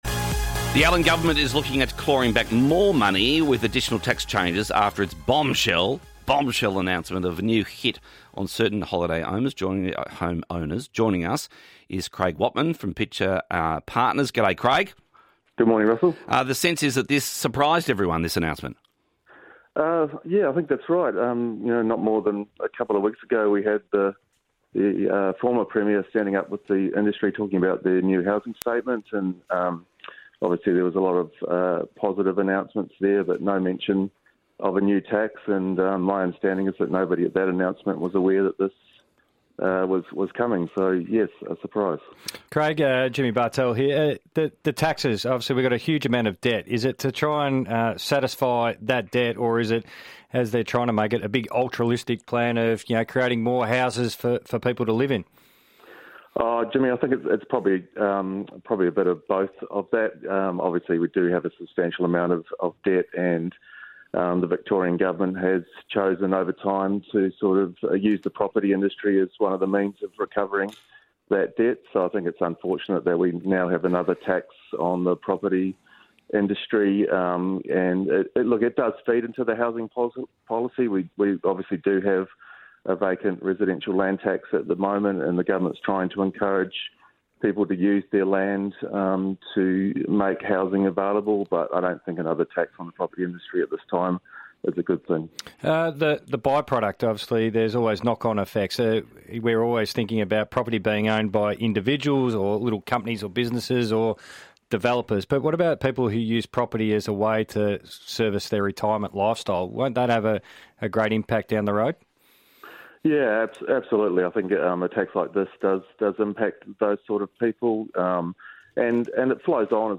Interview: Victorian government introduces a surprise new tax